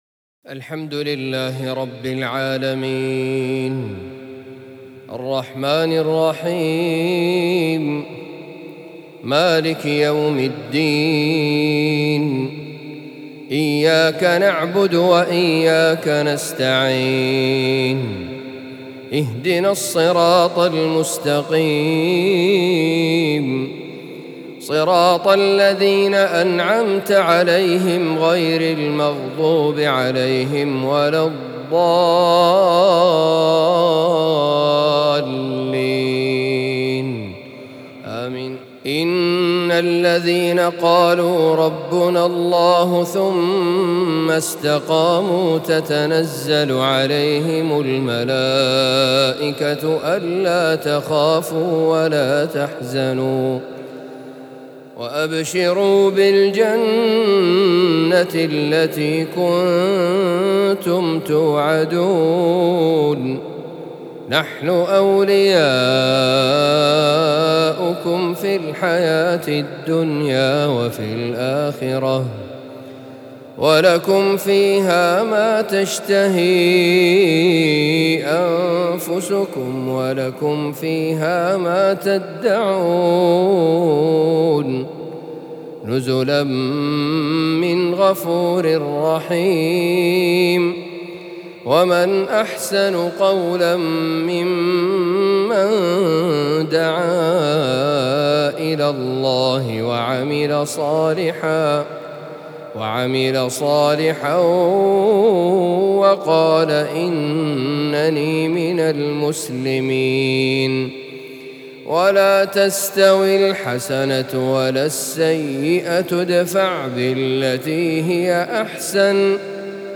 عشائية
بمسجد عبدالرحمن النملة ، حي الإسكان بالمدينة المنورة.